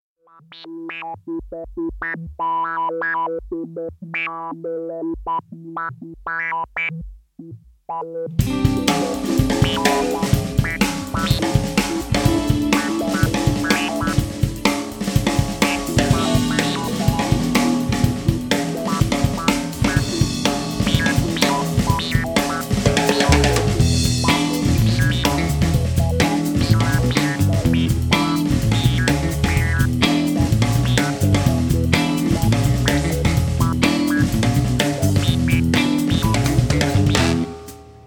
I'm wondering if this filter synth sound